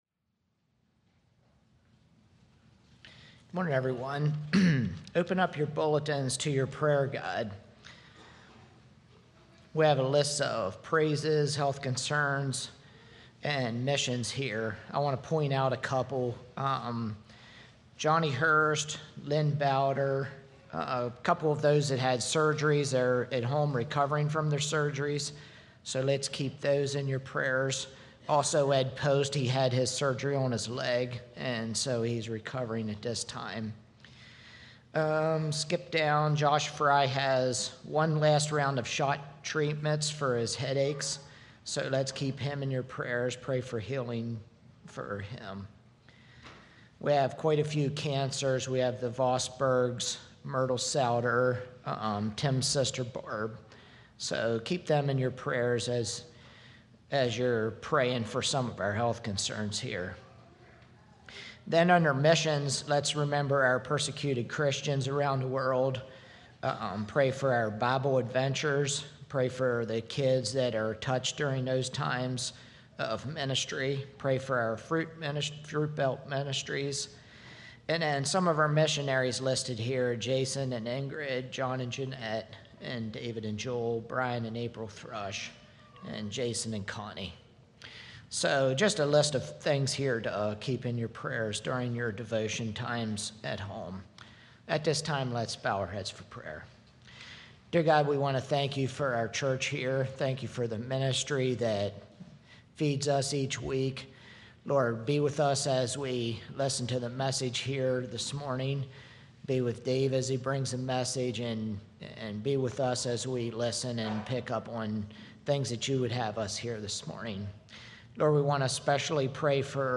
Are you fulfilling your calling as a married couple? *Baby dedication followed the end of the sermon.